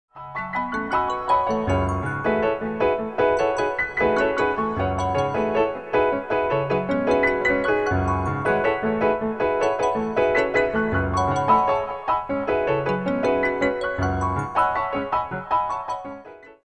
In this example, there are two ostinato patterns, one in the piano, one on the marimba (click